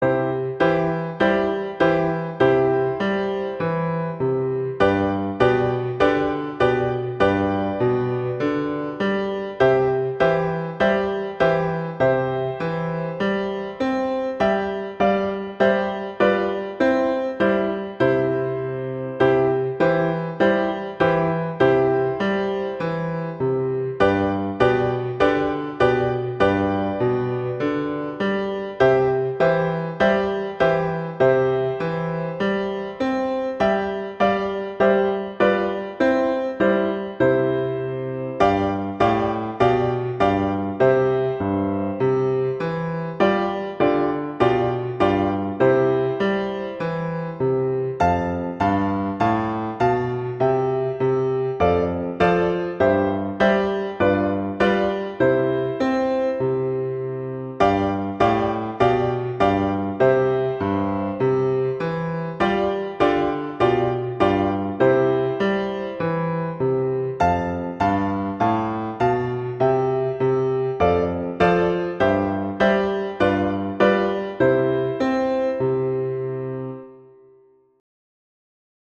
classical, instructional
C major